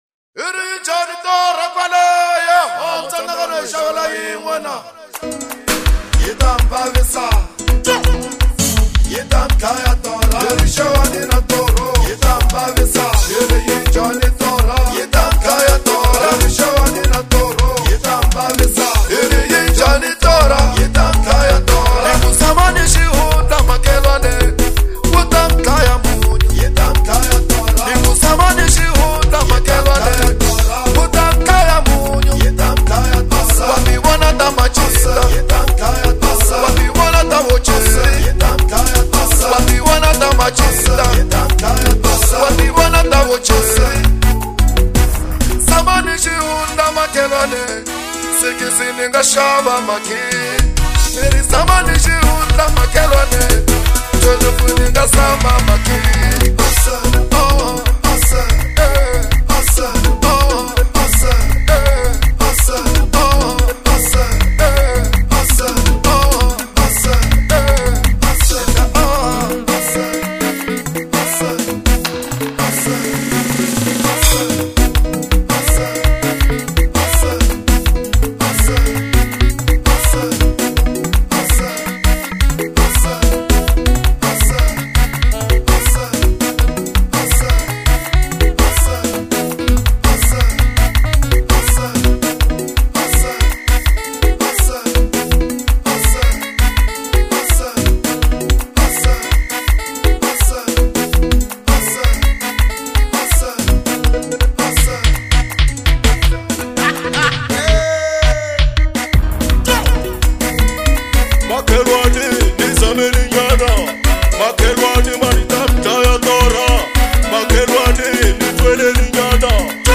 Xitsonga